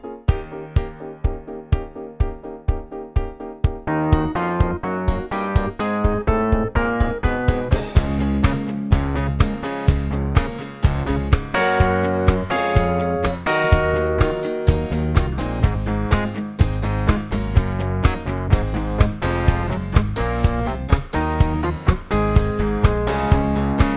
Two Semitones Down Pop (1970s) 3:01 Buy £1.50